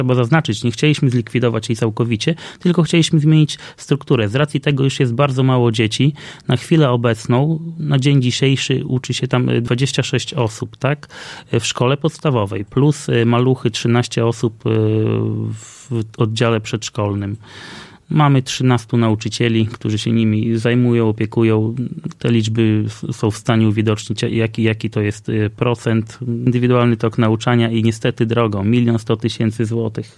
O szczegółach mówił w środę (03.04) w Radiu 5 Robert Bagiński, wójt gminy Giby.